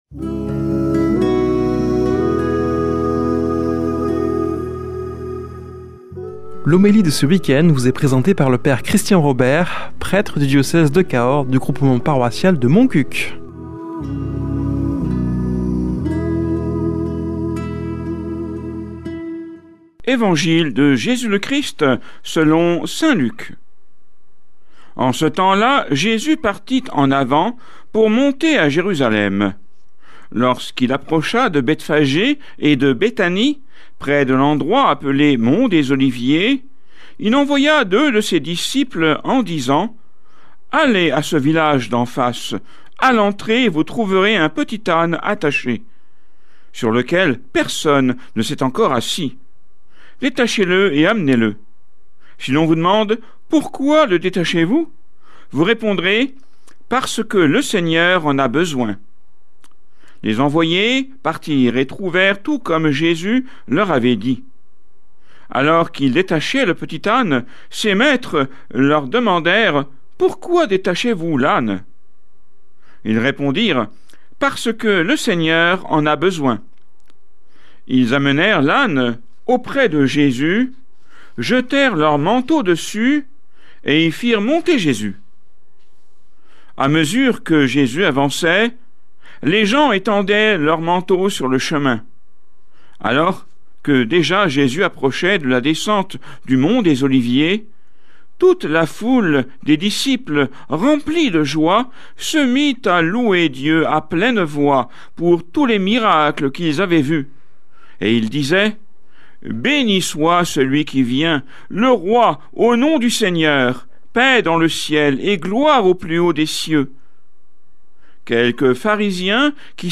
Homélie du 12 avr.